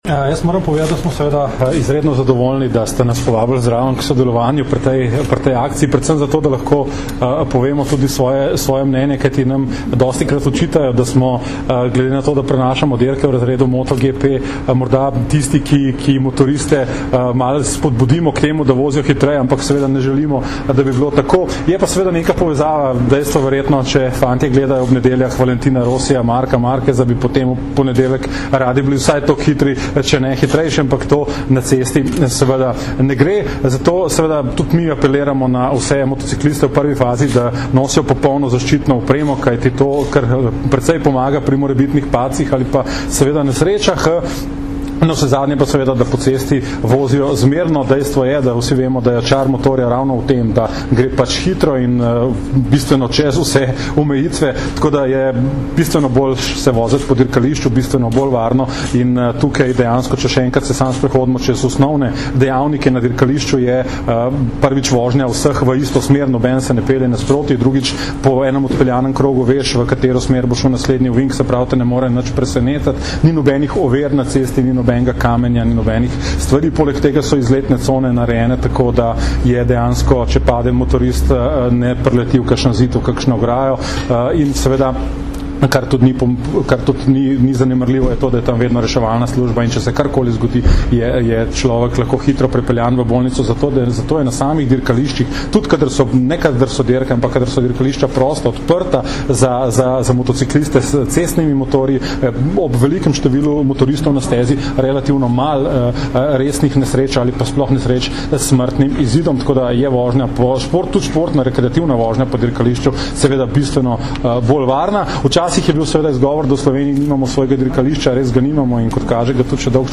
Zvočni zapis izjave